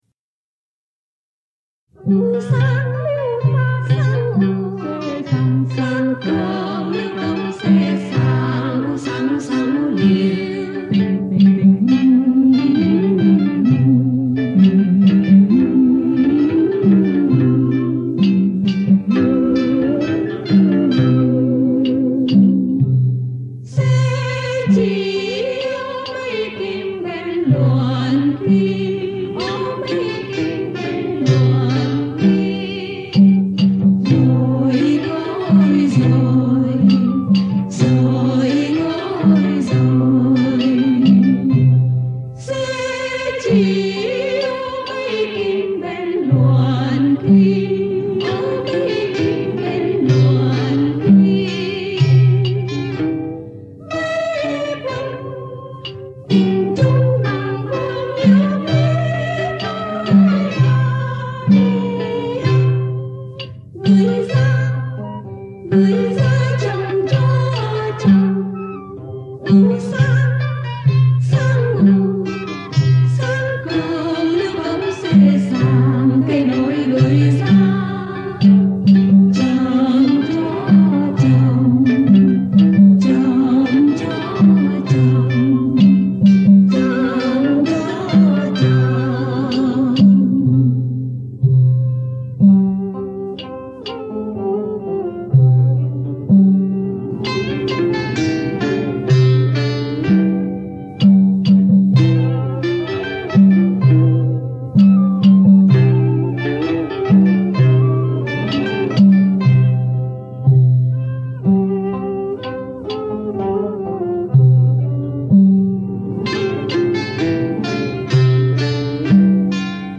Xe Chỉ Luồn Kim– Dân ca do Phạm Duy sưu tập.
Trình Bày: Ban Tam Ca Đông Phương (Pre-75)
Các bài dân ca miền suôi nằm trong các thể hát vặt, hát ví, hát quan họ, hát chèo, hát ả đào khi xưa như LÝ CÂY ÐA, QUA CẦU GIÓ BAY, CÂY TRÚC XINH, CHUỐC RƯỢU, SE CHỈ LUỒN KIM, TRẤN THỦ LƯU ÐỒN… được tôi phục hồi và hiện đại hoá.